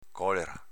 PAROLA IN DIALETO